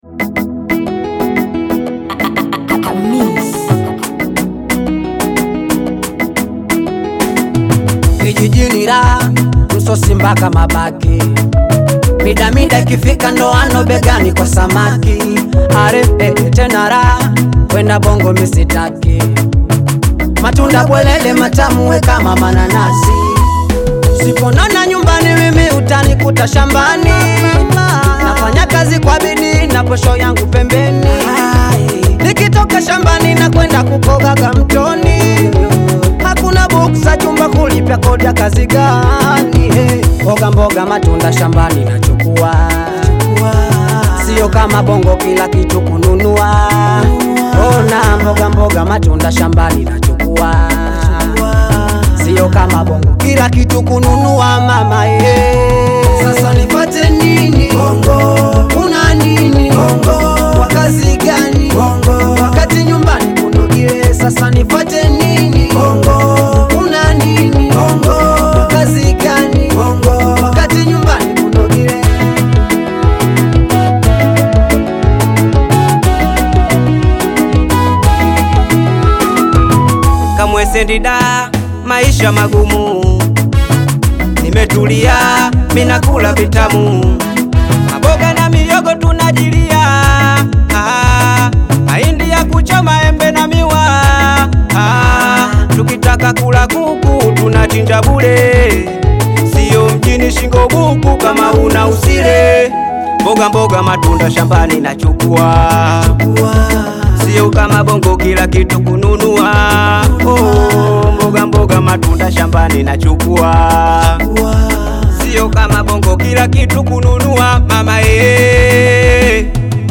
blending traditional rhythms with modern beats
Genre(s): Bongo flava